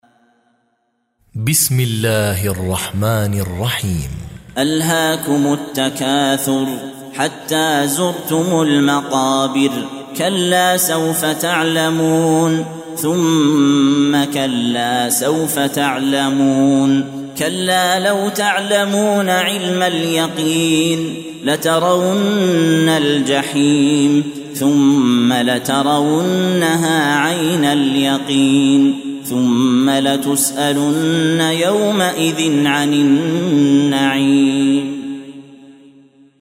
Surah Sequence تتابع السورة Download Surah حمّل السورة Reciting Murattalah Audio for 102.